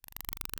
beetle.wav